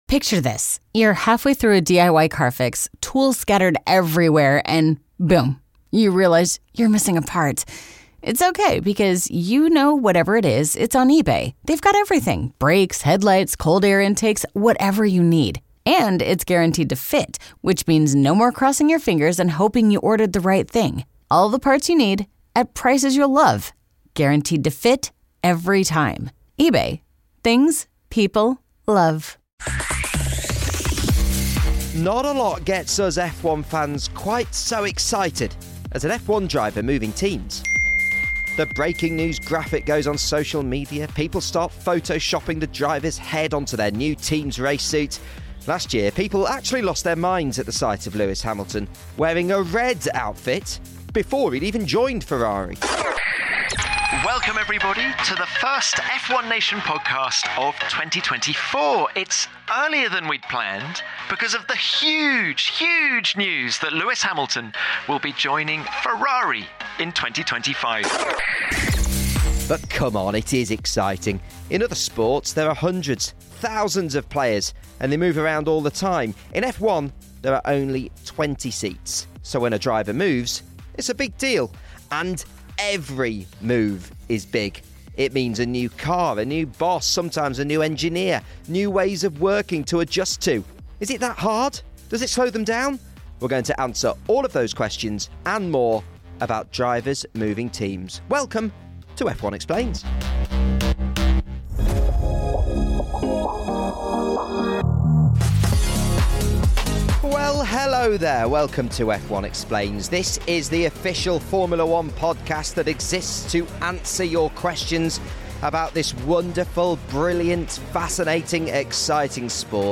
Then, 2009 World Champion Jenson Button returns to the podcast to share his experiences of moving from Williams to Honda to McLaren.